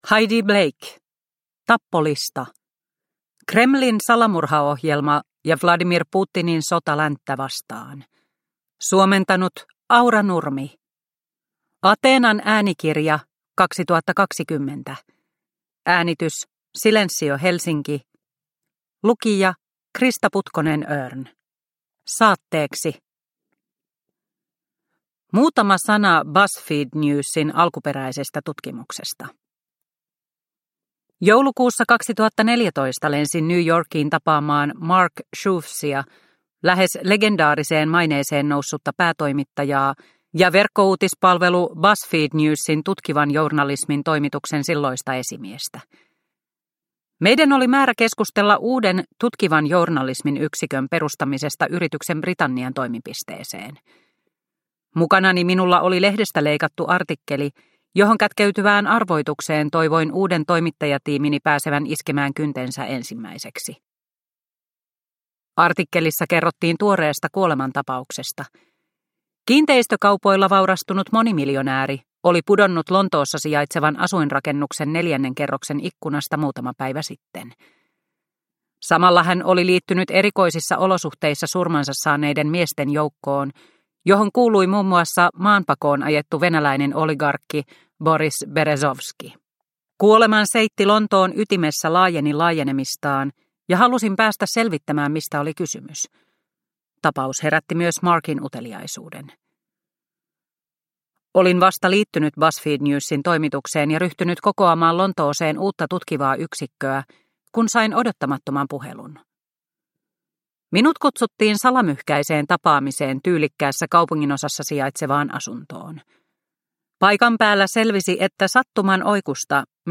Tappolista – Ljudbok – Laddas ner